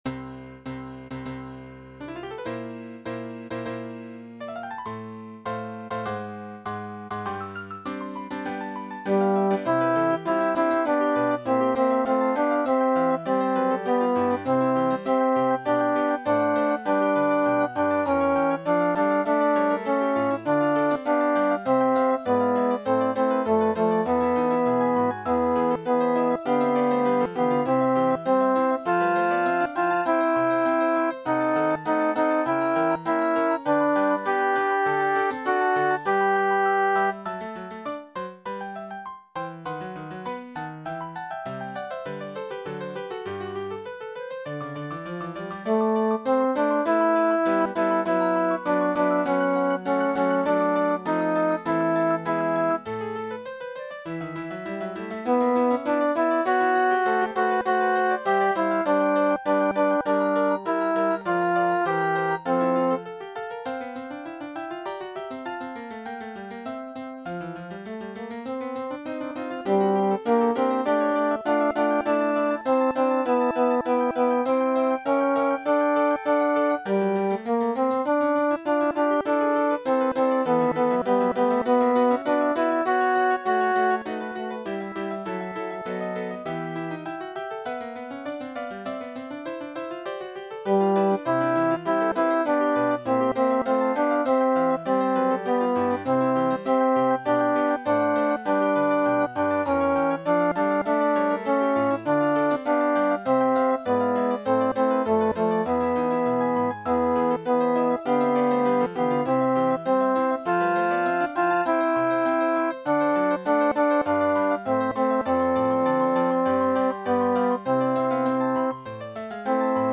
Part I - MIDI S A T B Full